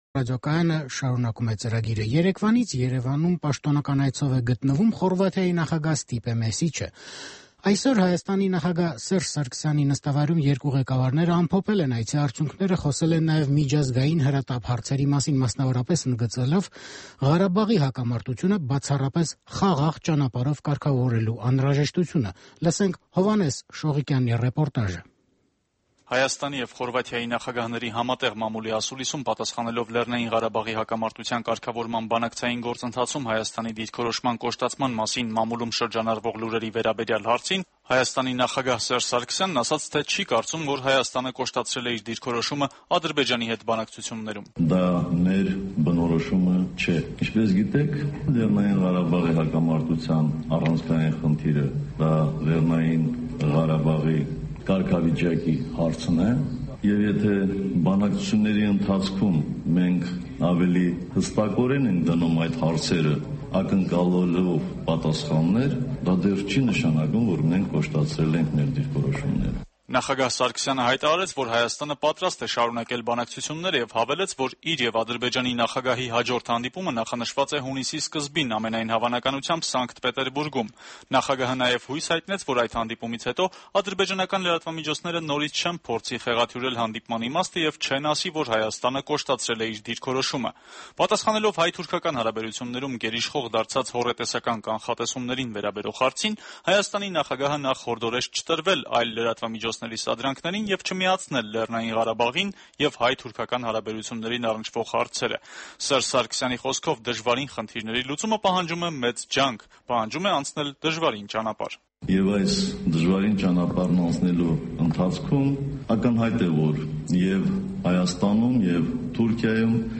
Սերժ Սարգսյանի եւ Ստյեպան Մեսիչի համատեղ ասուլիսը